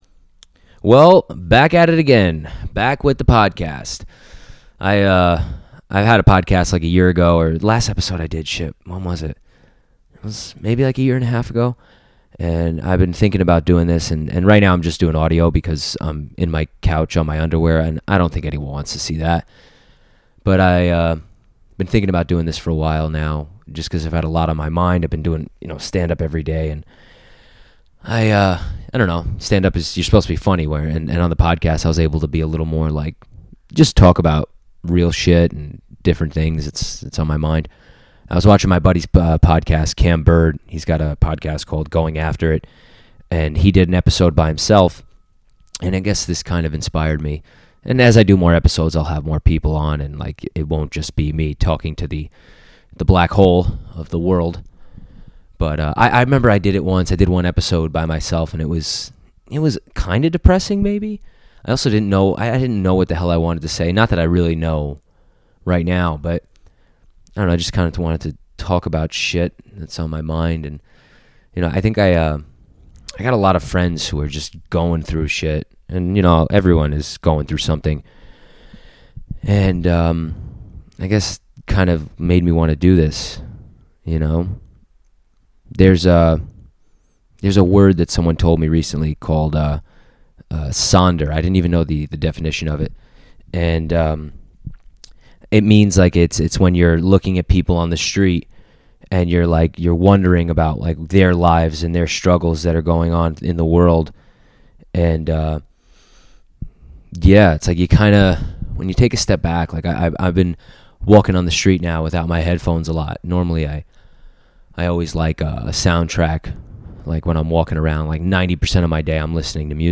I decided to do the first episode back alone and talk about ideas and topics that have been floating around my head, less funny stuff and more of a real conversation with myself. I talk about stand up, relationships, living the life you actually want to live, cherishing all moments (even the bitter ones), feeling alive, trusting your gut, love/heartbreak, recent events with important people in my life, comparisons between writing material and arguments with lovers, and just raw truth about myself lately.